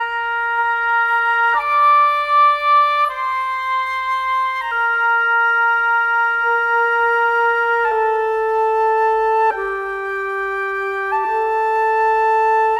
Rock-Pop 17 Winds 02.wav